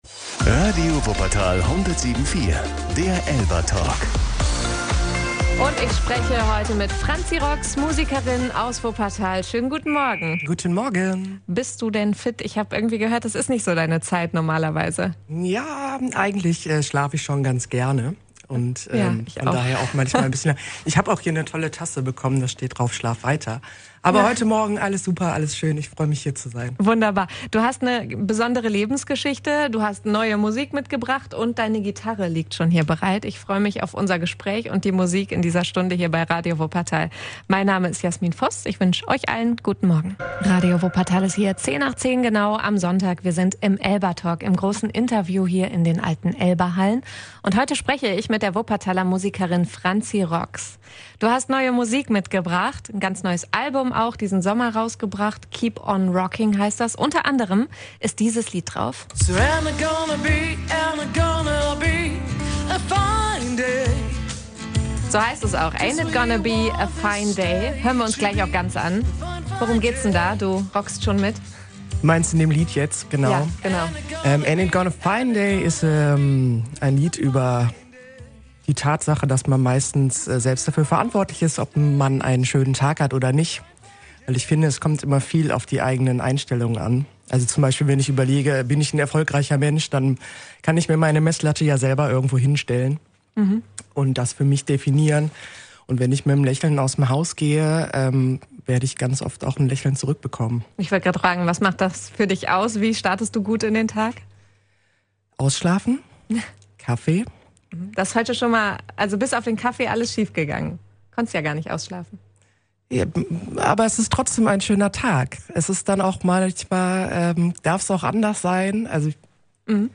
Ihre Geschichte erzählt sie im ELBA-Talk.